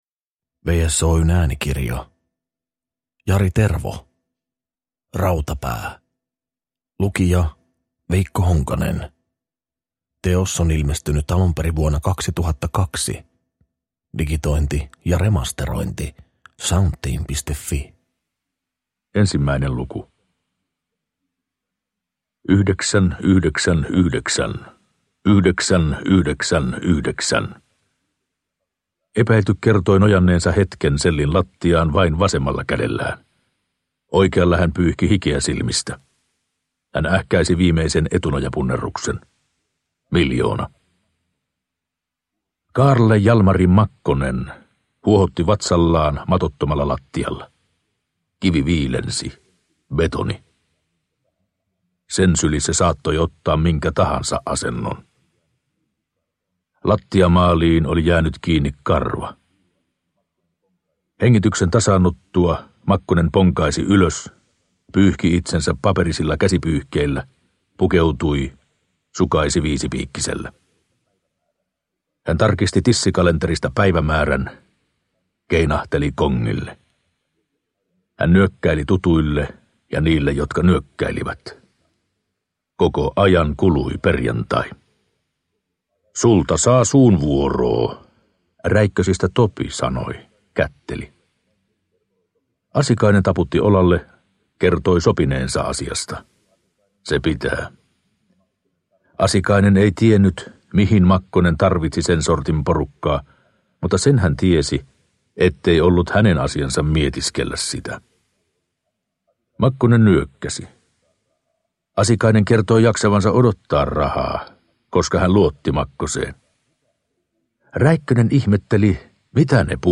Rautapää – Ljudbok